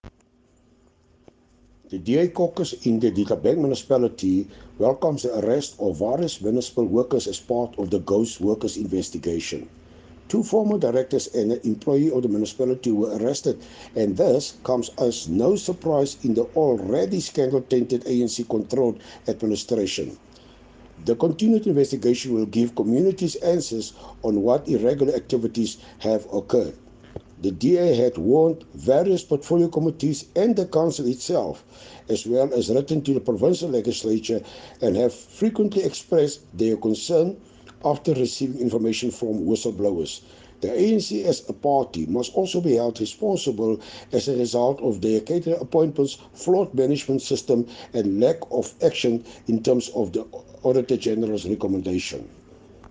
Afrikaans soundbites by Cllr Hilton Maasdorp.